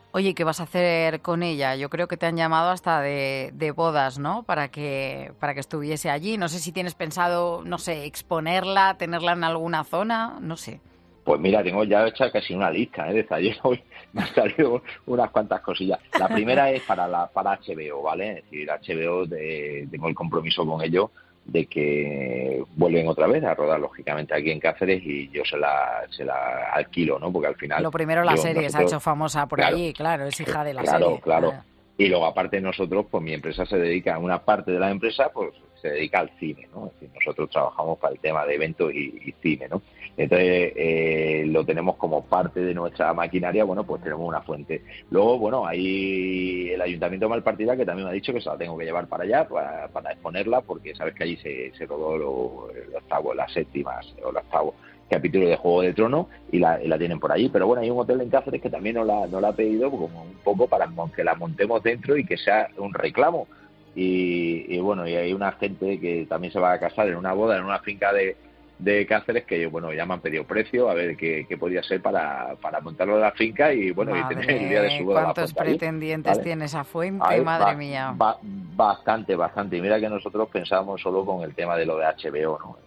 Aquí tienes el audio en el que él mismo cuenta qué consecuencias ha tenido para él ser el propietario de este objeto tan preciado.